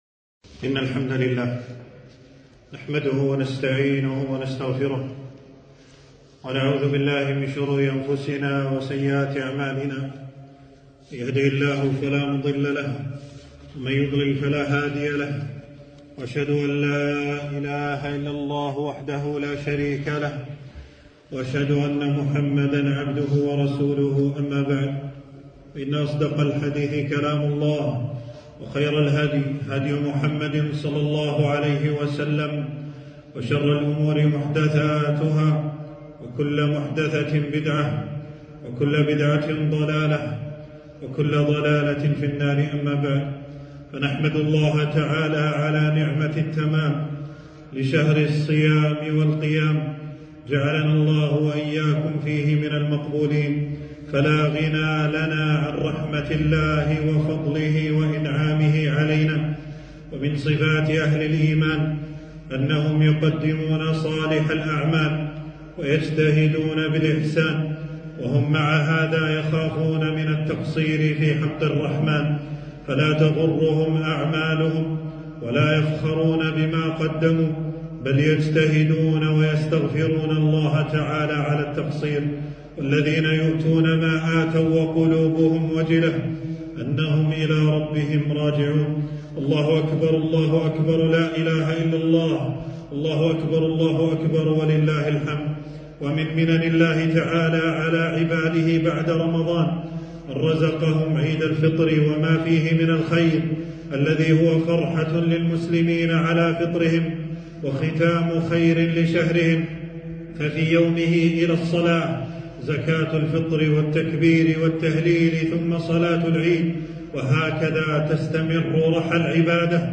خطبة عيد الفطر 1442هـ